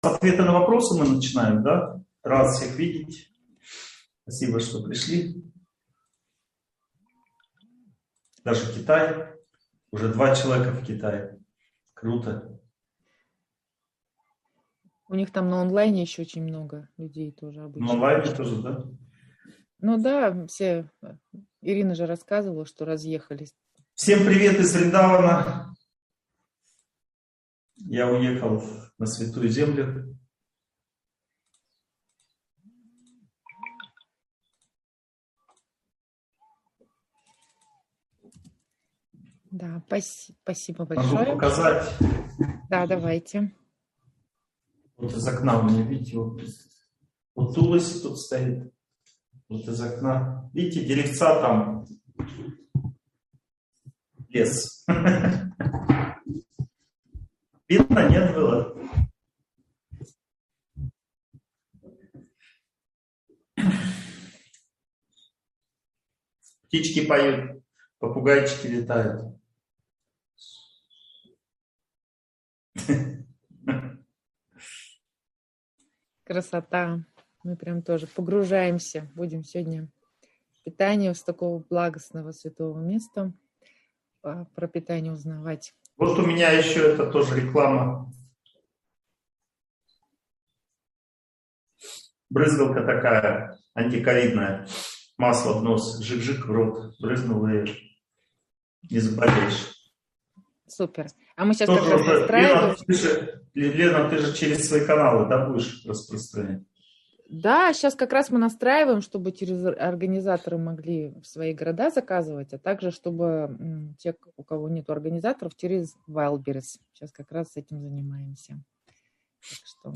Питание. Углубленное изучение темы. Часть 4 (онлайн-семинар, 2022)